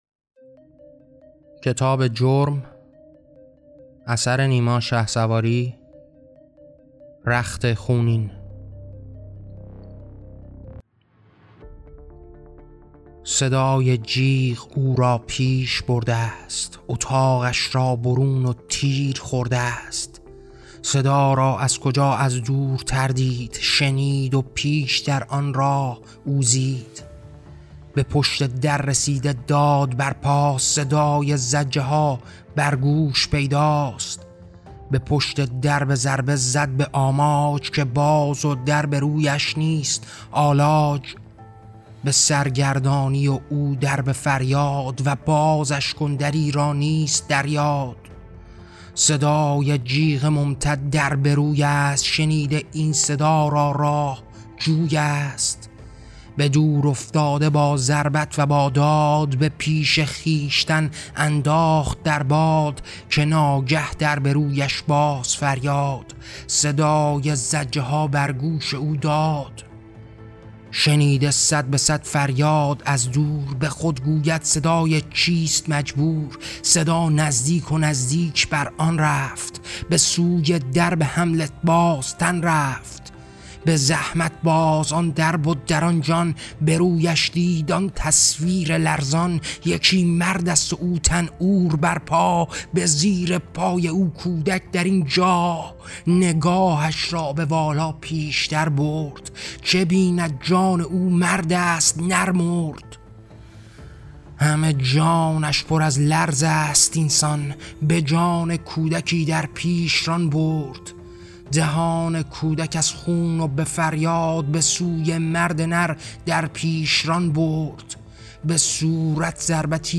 پخش داستان کوتاه صوتی رخت خونین